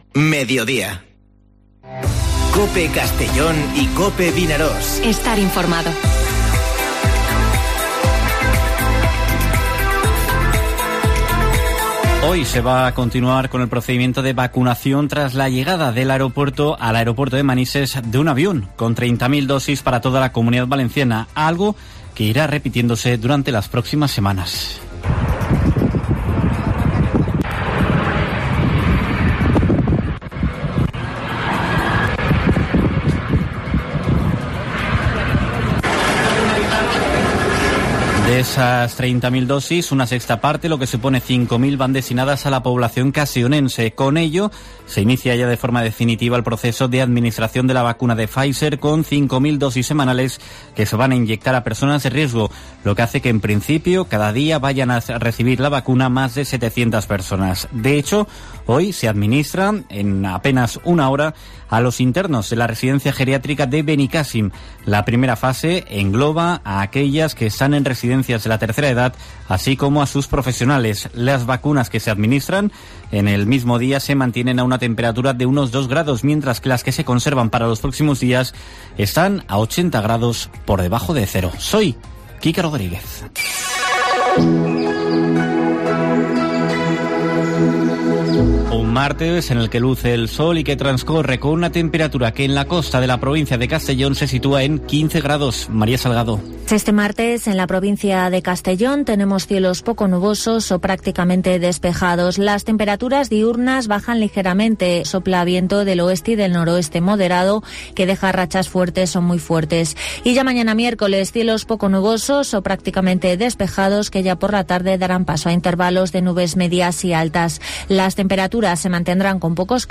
Informativo Mediodía COPE en la provincia de Castellón (29/12/2020)